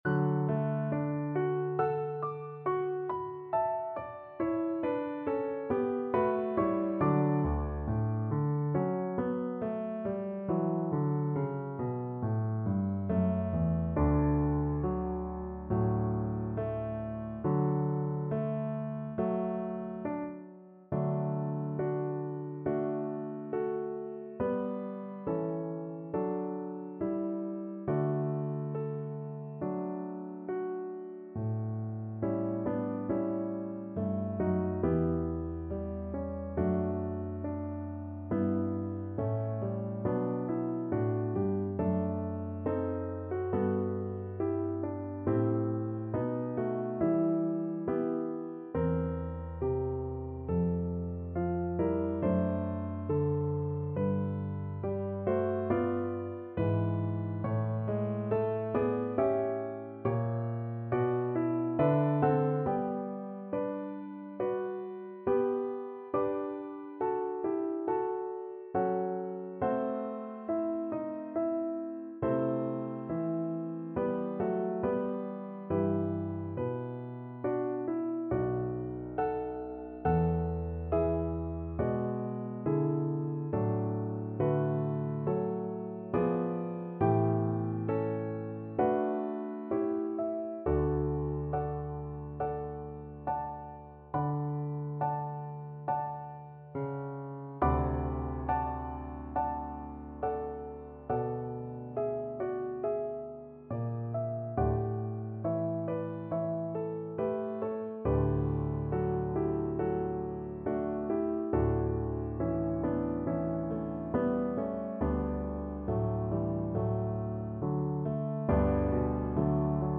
2/2 (View more 2/2 Music)
Slow =c.69
D major (Sounding Pitch) A major (French Horn in F) (View more D major Music for French Horn )